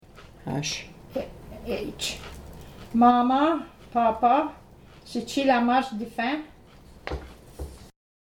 Reading Indigenous Translations of Riel: Heart of the North -- Audio Recordings